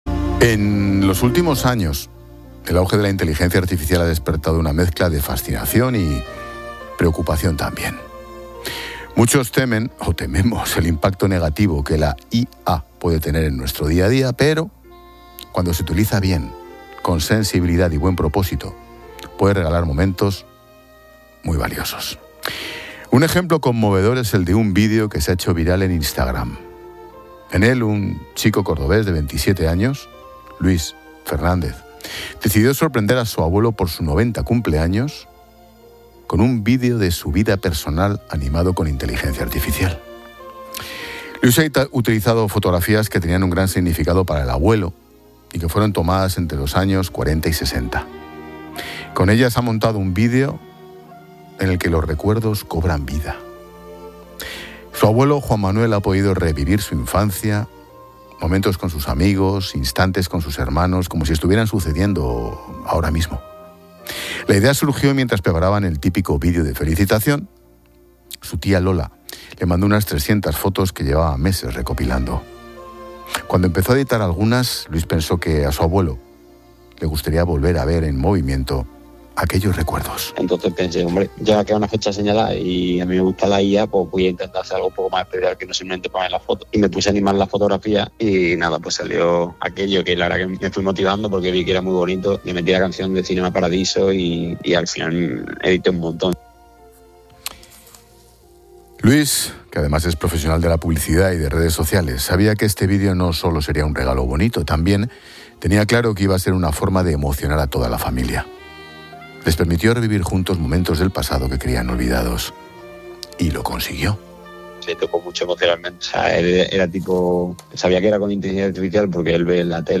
Ángel Expósito cuenta la historia